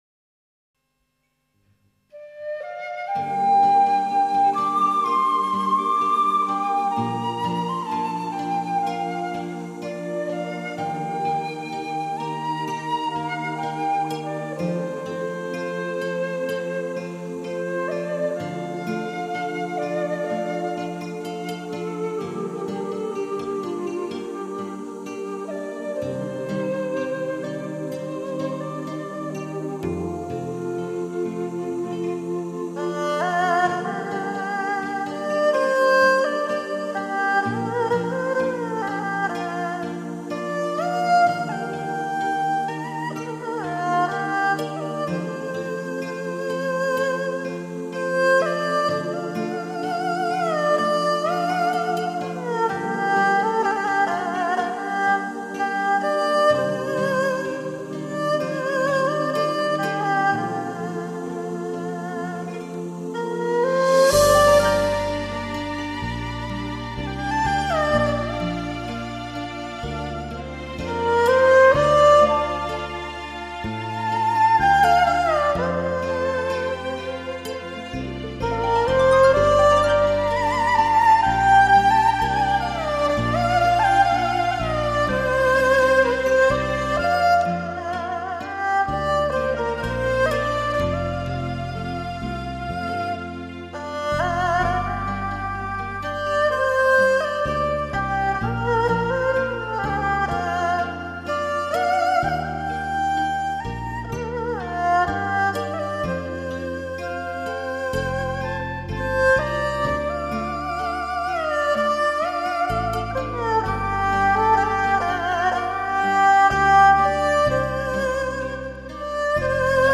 音质很棒。带着中国民族音乐浓郁风味的经典新世纪休闲音乐，绝对的超值珍藏品。
旋律清幽抒情